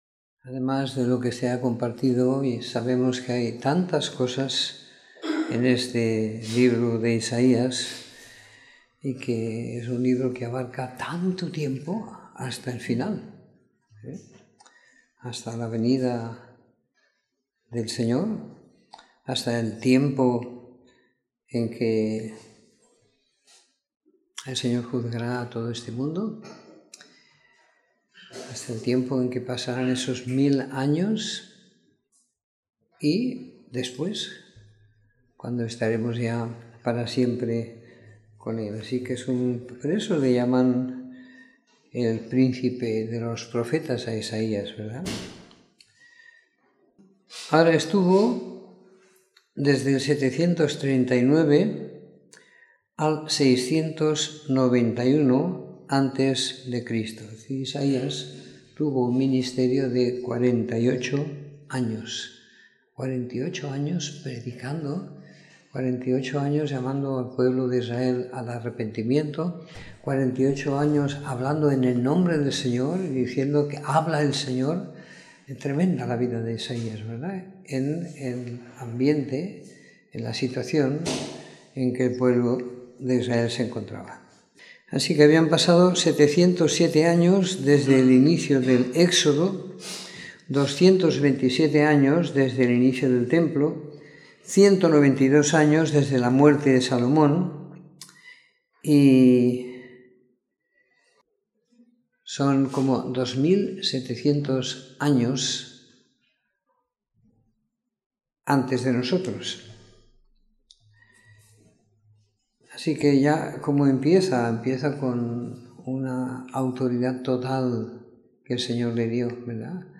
Comentario en Isaías 1-11 - 21 de Septiembre de 2018
Comentario en el libro de Isaías del capítulo 1 al 11 siguiendo la lectura programada para cada semana del año que tenemos en la congregación en Sant Pere de Ribes.